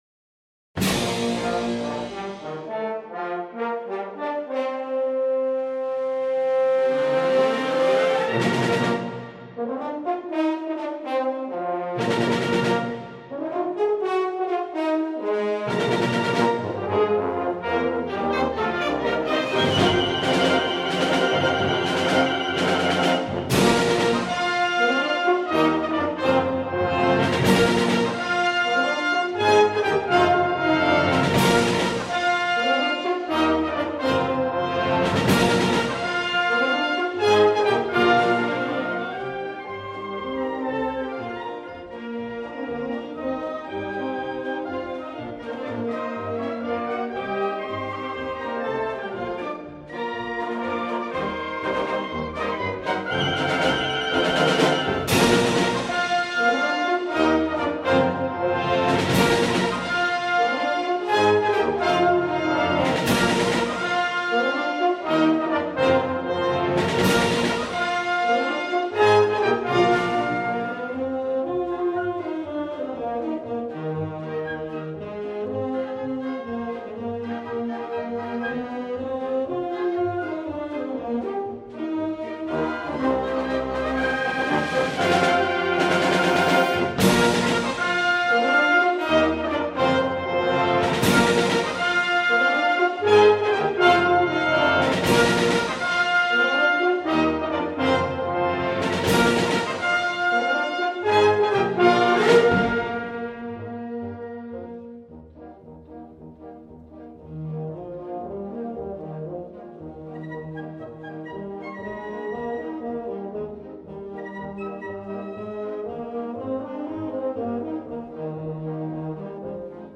for Wind Band
A concert fantasy which your Horn section will relish!!